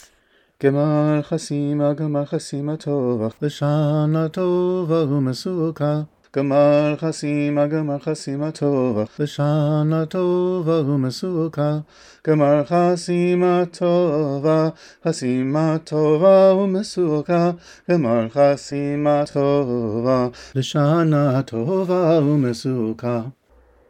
This dance nigun is indeed the familiar one from the end of Birkat Ha-Mazon (the blessing after a meal), starting at Barukh hagever. The Chabad version gives the tune some special tweaks and rhythms that make it singable again.
here to a quick recording I made of the tune -- for now, the original recording of Chabadniks dancing to this tune has gone missing!